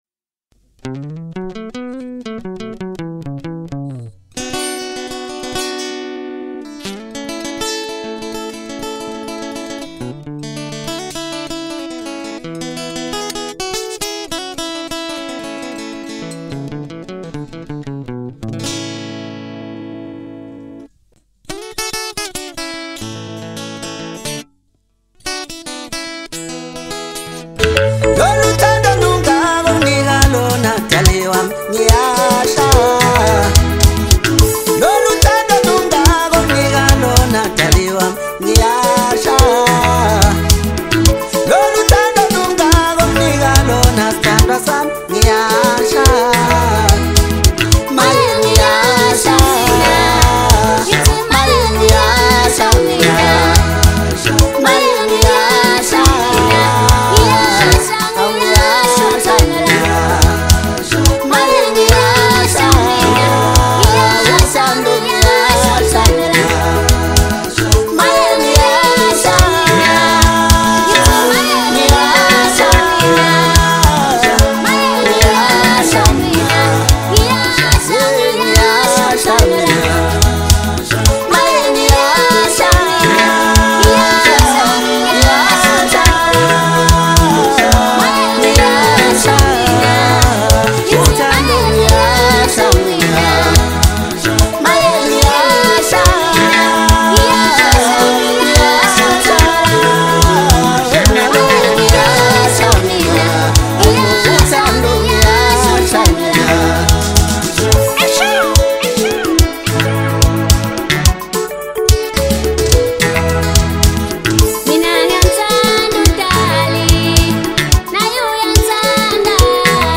Home » Maskandi » DJ Mix